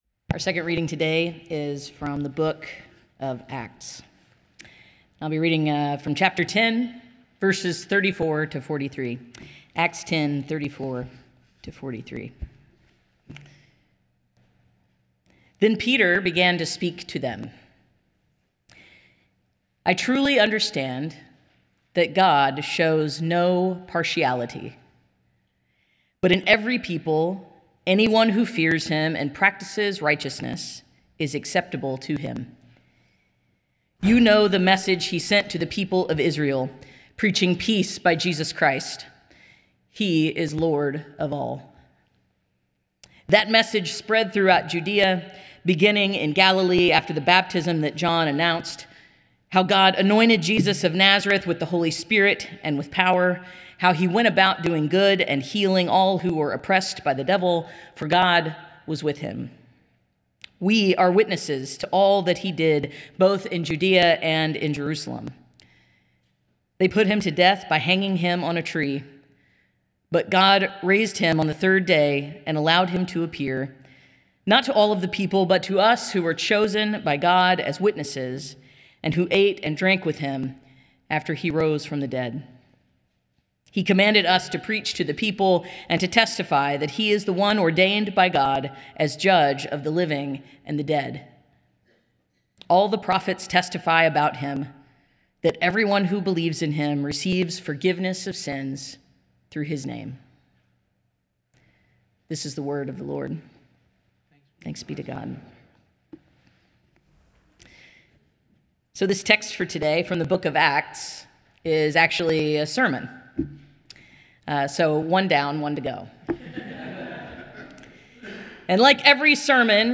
Nassau Presbyterian Church Sermon Journal January 11, 2026 Sermon Jan 11 2026 | 00:21:32 1x 00:00 / 00:21:32 Subscribe Share Apple Podcasts Spotify Amazon Music Overcast RSS Feed Share Link Embed